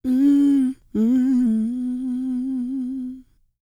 E-CROON P308.wav